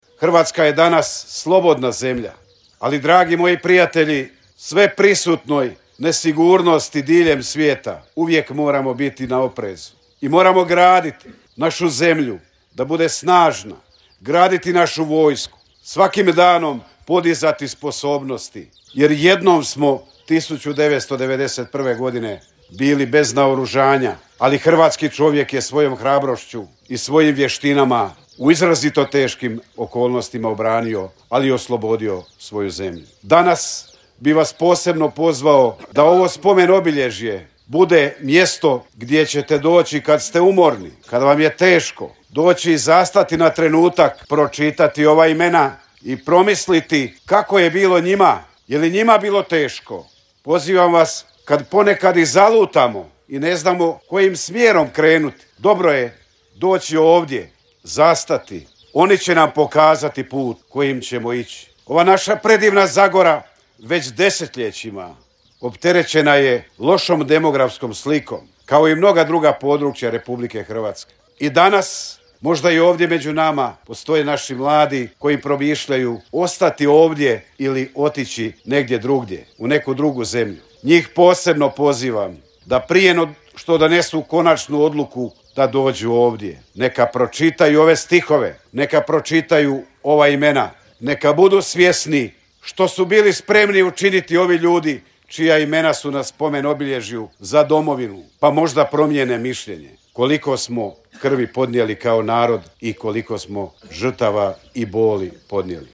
Pokraj crkve Uznesenja Marijina u Mirlović Zagori svečano je jučer poslijepodne otkriven spomenik posvećen poginulim hrvatskim braniteljima s tog područja.
‘Svojim životima platili su našu slobodu’, istaknuo je ministar hrvatskih branitelja Tomo Medved, dodajući kako demografska slika ovoga područja nije dobra.